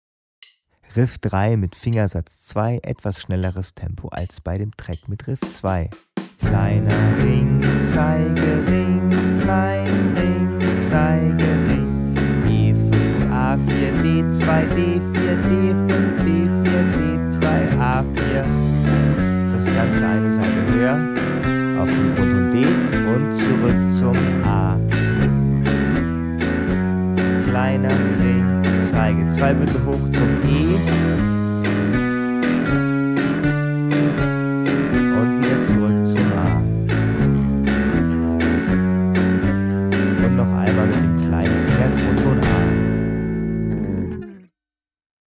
Als MODERIERTE MP3-CD mit mehr als vier Stunden Spieldauer bietet sie präzise Anleitungen zu Fingersätzen, rhythmischen Besonderheiten und viele Playbacks zum Mitspielen.
sound_clippingRiffs verschiebbar
129_Riff_3_FS_2_Playback.mp3